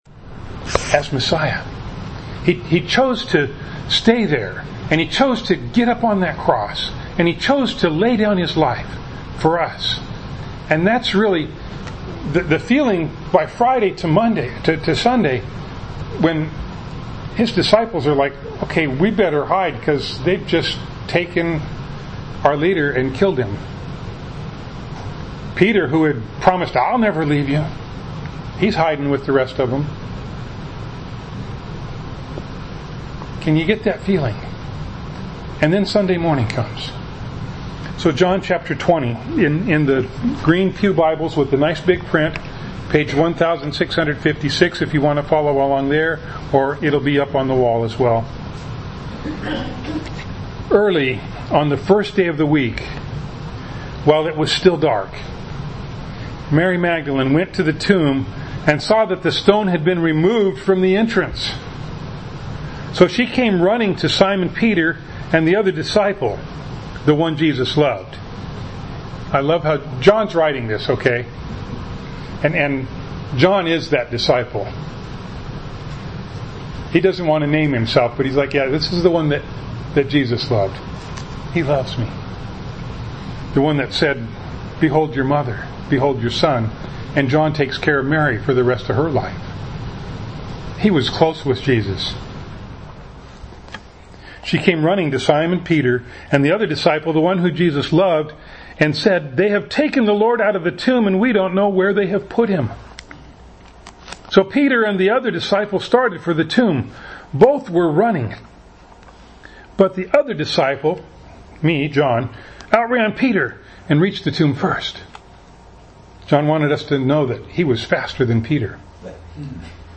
James 4:6b-7 Service Type: Sunday Morning Bible Text